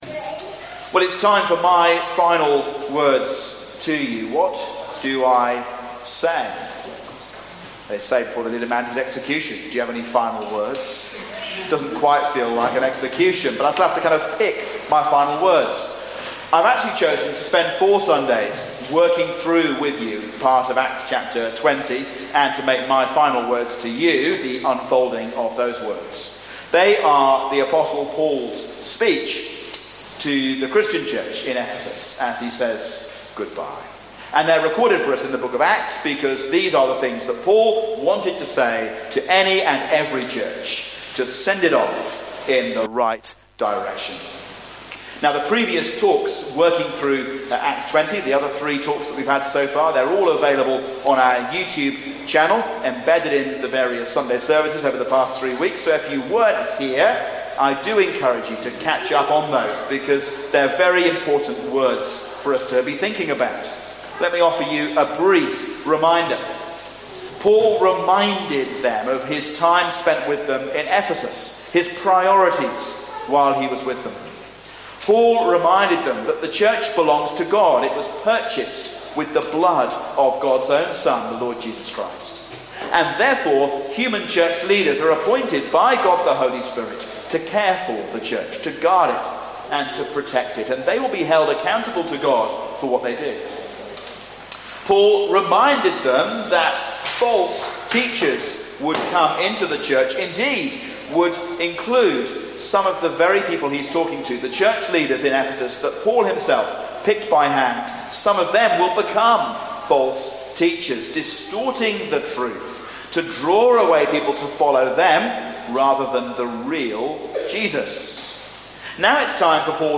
Farewell 4: Grace and Generosity, A sermon